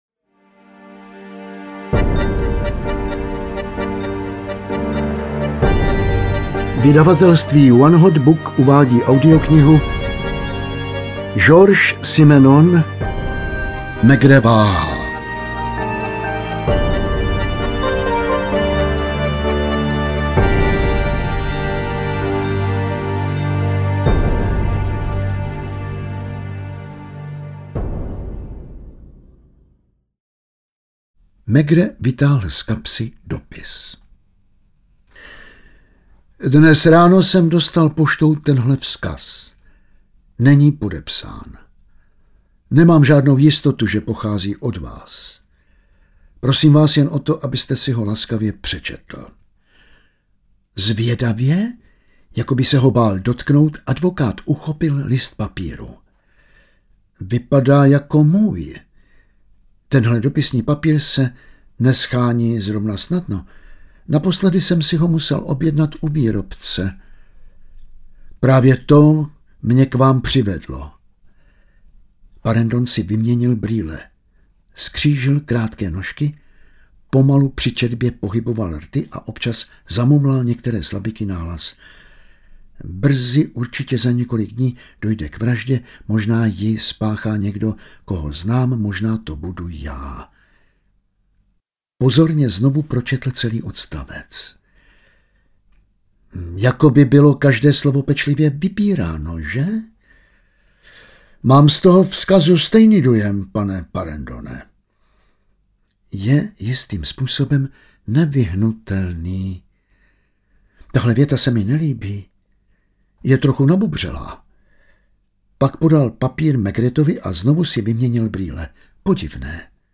Maigret váhá audiokniha
Ukázka z knihy
• InterpretJan Vlasák